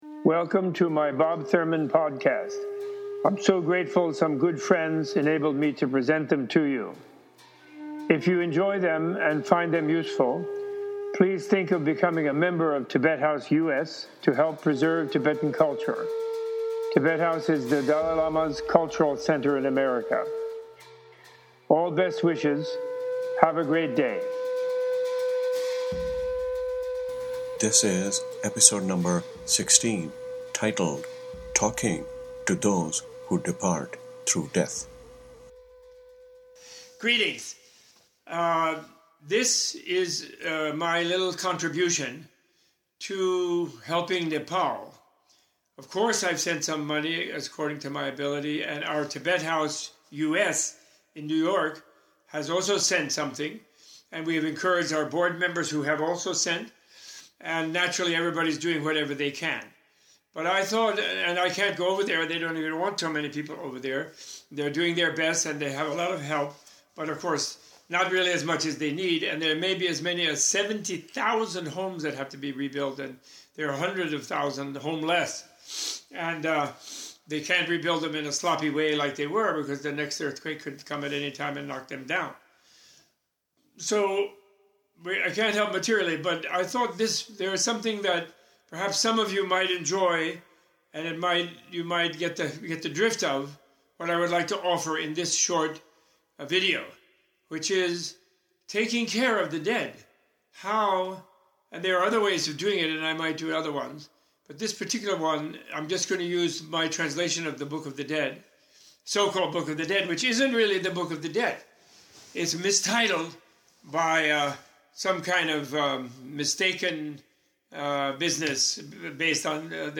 Robert A.F. Thurman leads a prayer for those who died in the Nepal earthquakes from The Tibetan Book of the Dead. In the Tibetan view of the dying process, death is a gateway into a journey through a dream-like landscape (the bardo), that can take up to 49 days, and sometimes much longer, if the person has strong amount of unfinished business from the previous life.